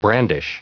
Prononciation du mot brandish en anglais (fichier audio)
Prononciation du mot : brandish